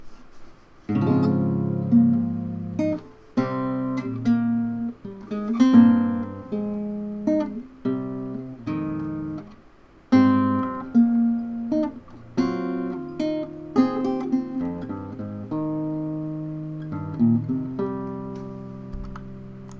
did this sightread, sorry its a bit sloppy